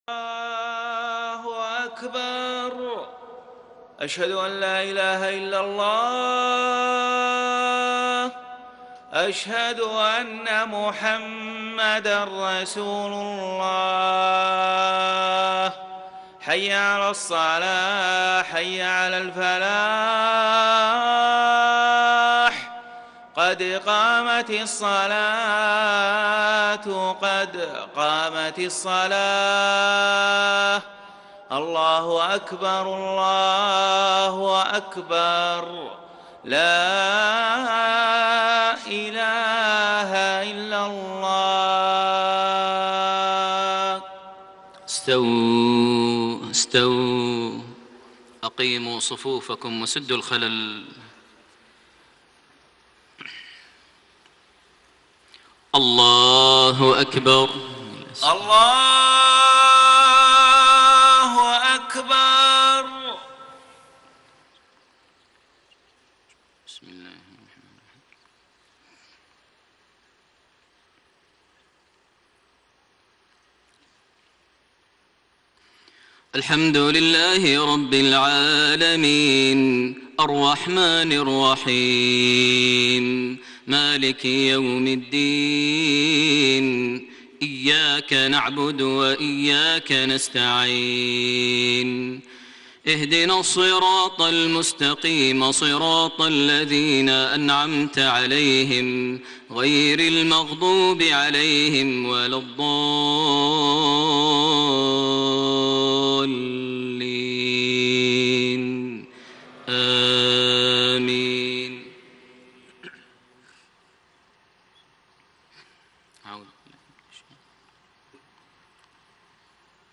صلاة الفجر 7 شعبان 1433هـ | من سورة الانعام 14-32 > 1433 هـ > الفروض - تلاوات ماهر المعيقلي